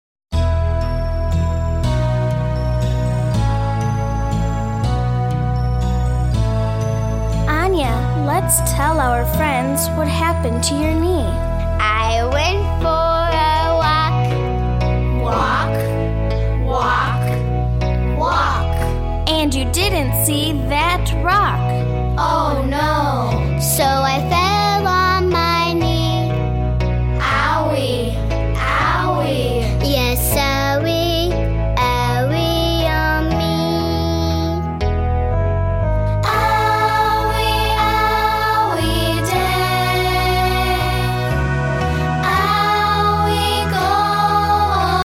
-Kids and adults singing together and taking verbal turns